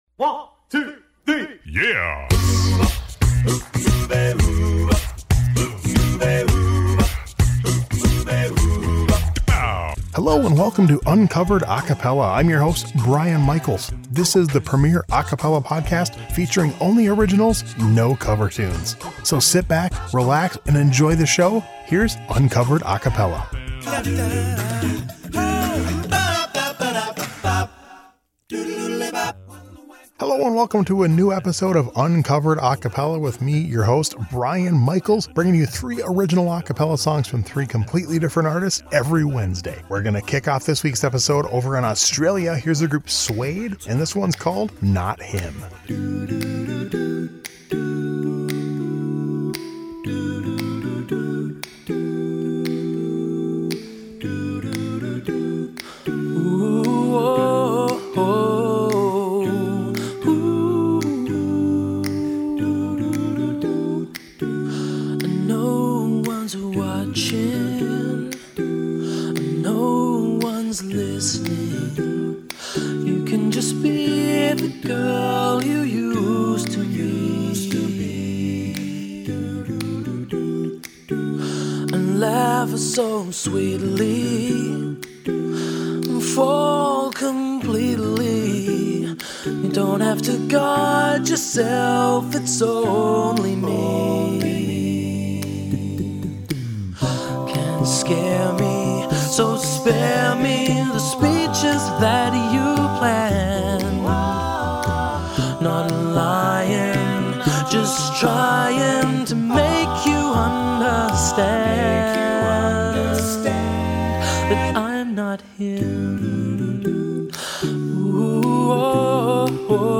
3 original a cappella songs every week!